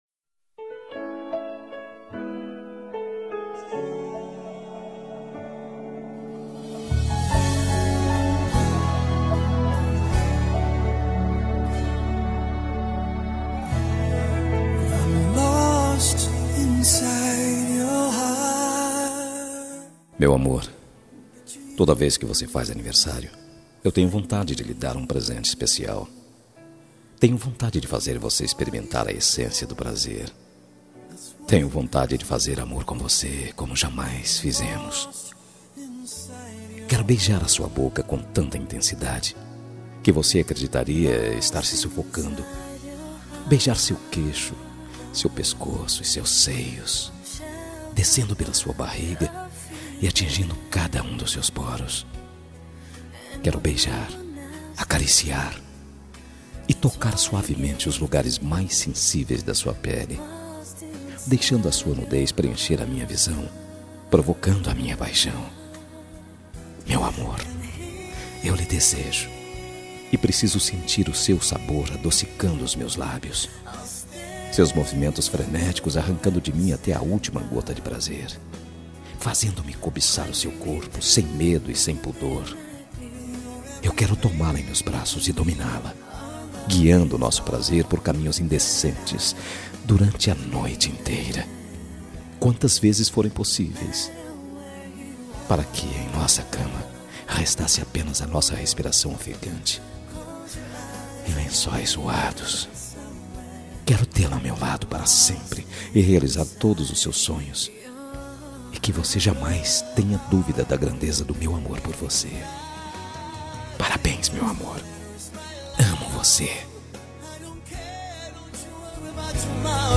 Telemensagem de Aniversário Romântico – Voz Masculina – Cód: 202139 – Picante